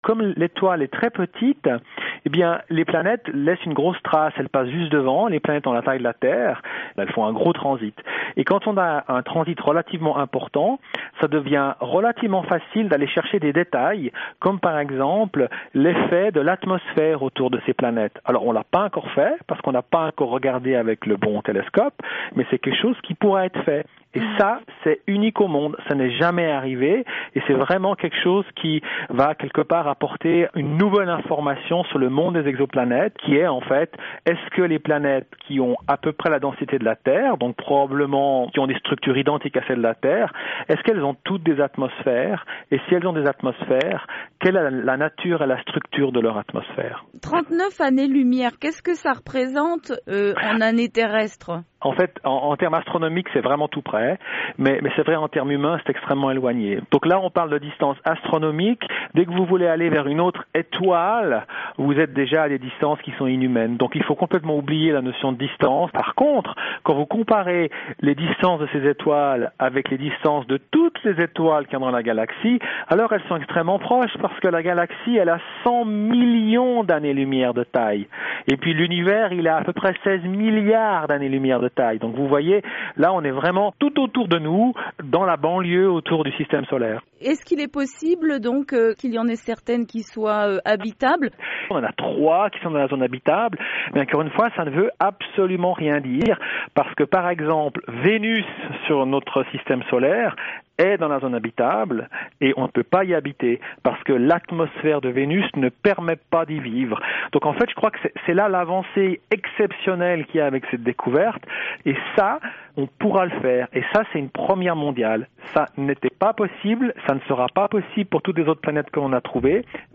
Entretien VOA du 23 février avec Didier Queloz, colauréat du Nobel de physique 2019: